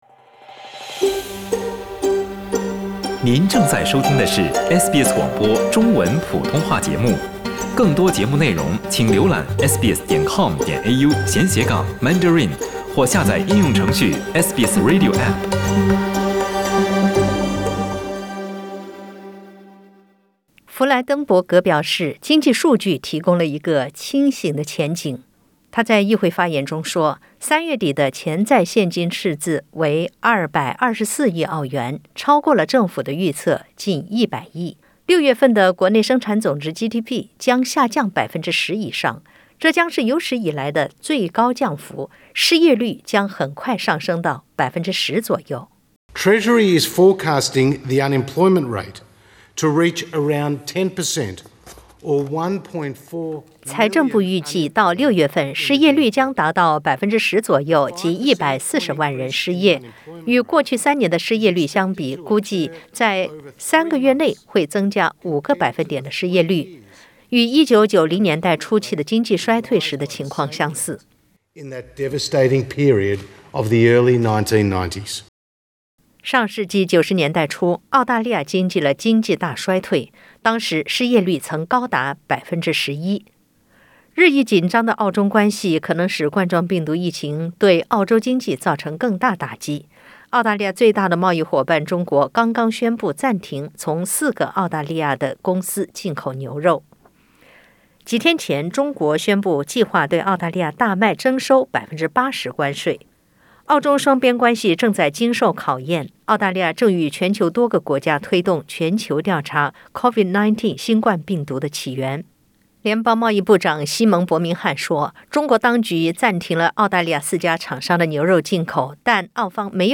3月底的现金赤字为224亿澳元，超过政府预测近100亿。失业率将可能很快升高至10%。点击上图收听报道。